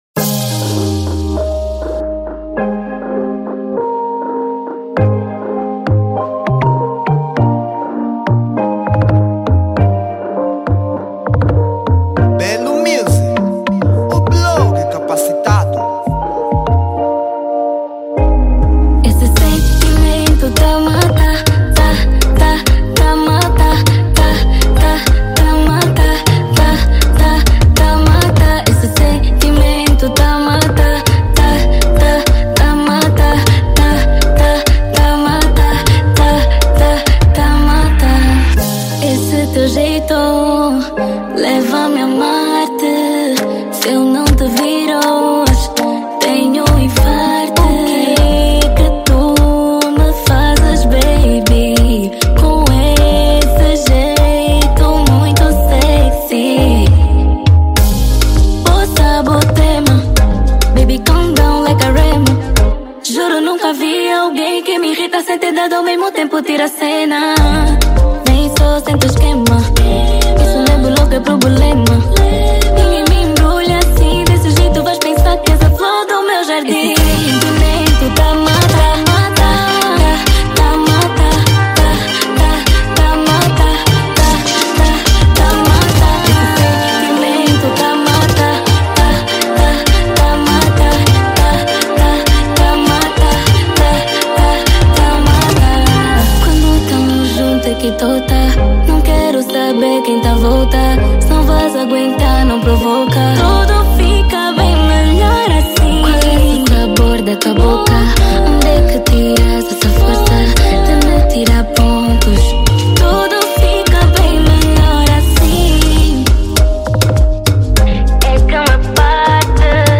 Género : Dance